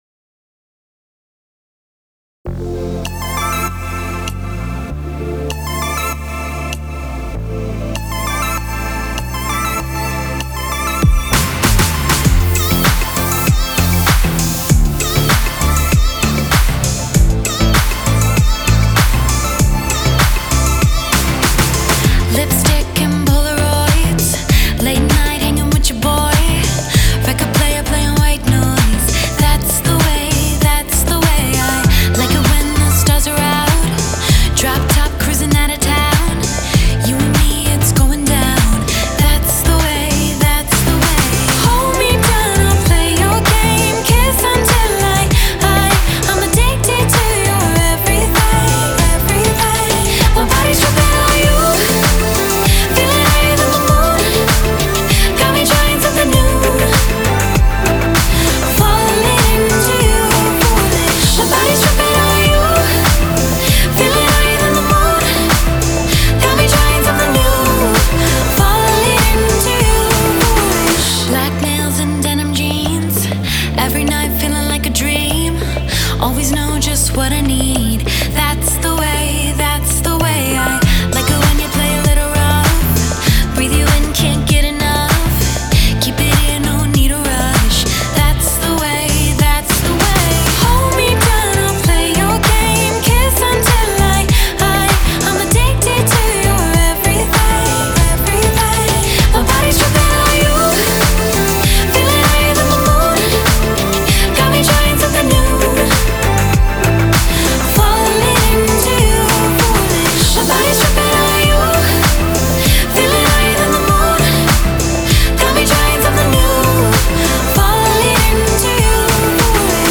BPM98
Audio QualityPerfect (High Quality)
Except she's actually a good singer.